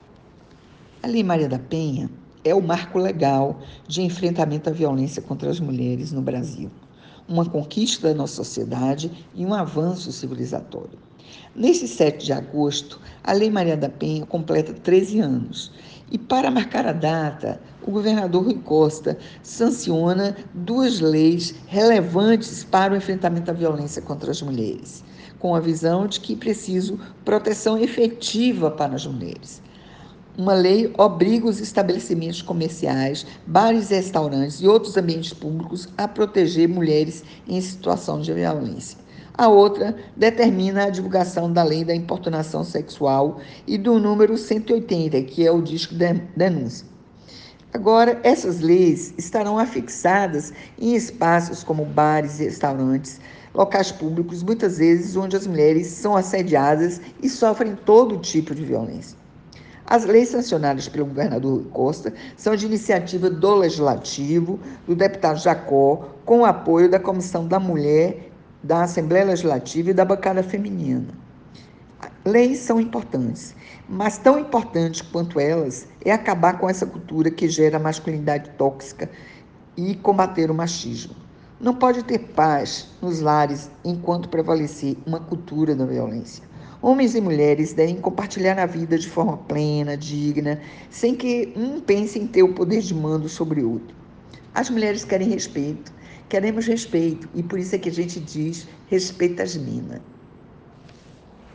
Sonora-da-secretária-de-Políticas-para-as-Mulheres-da-Bahia-Julieta-Palmeira.ogg